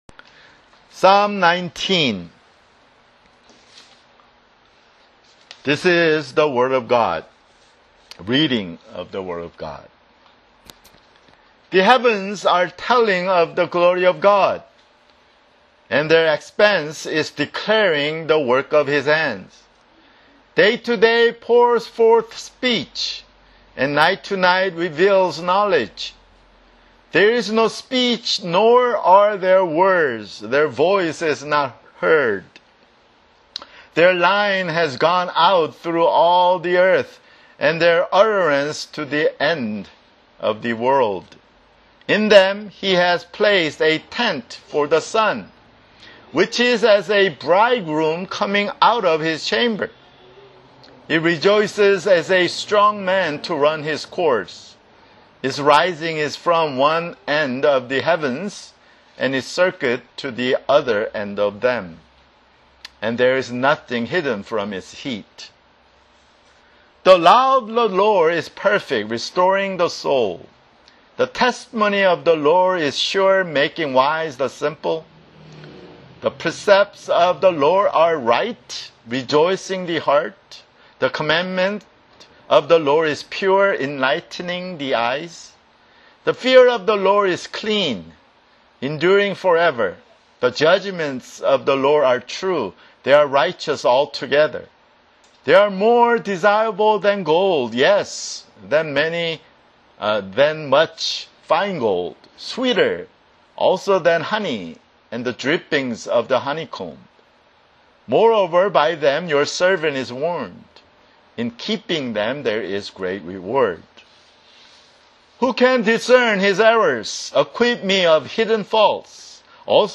[Sermon] Psalms (17)